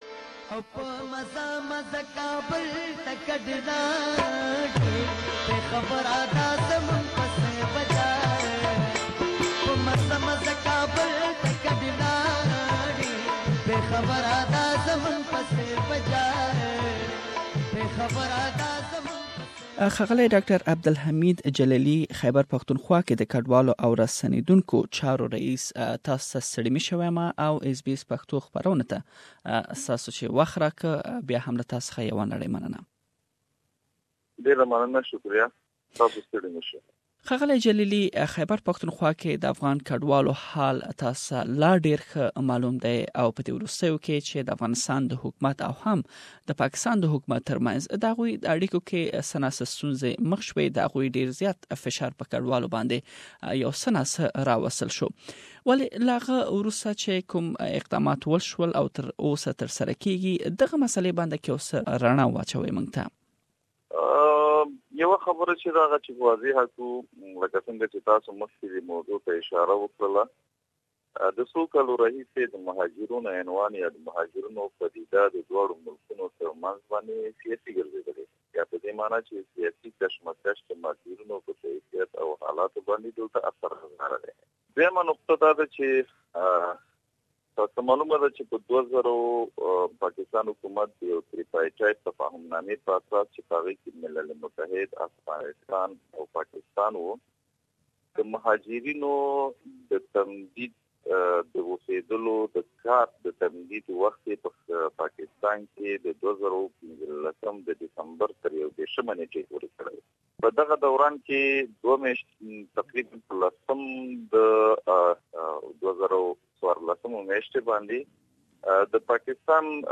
In Khyber Pakhtunkhowa the Head of Afghan Refugees and Returnees, Dr Hamid Jalili, says that Afghan refugees are now returning to their country in record high numbers. We have interviewed Mr Jalili on the recent developments that you can listen to his full interview here.